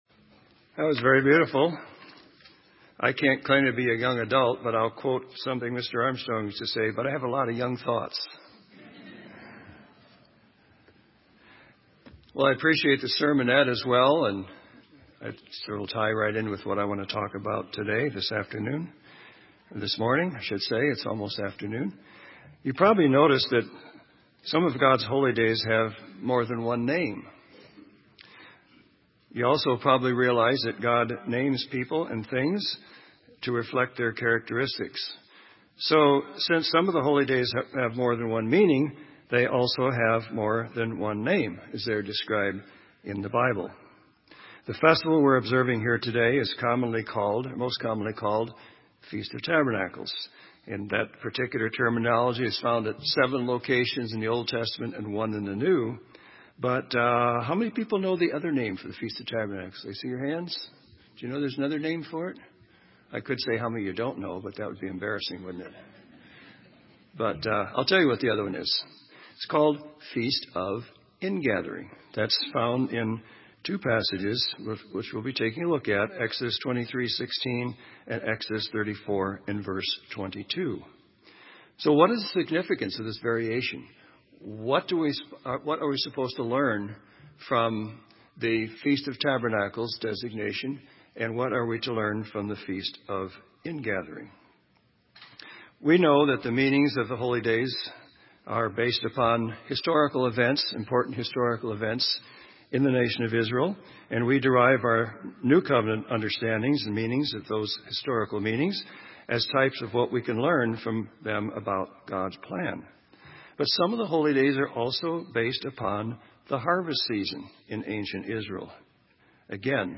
This sermon was given at the Bend, Oregon 2015 Feast site.